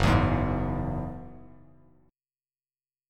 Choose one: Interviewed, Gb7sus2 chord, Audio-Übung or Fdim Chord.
Gb7sus2 chord